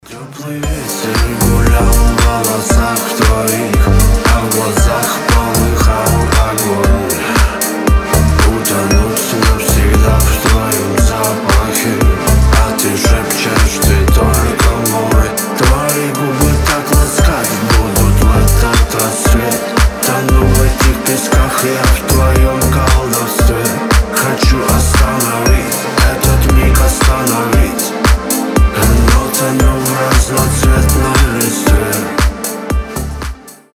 • Качество: 320, Stereo
мелодичные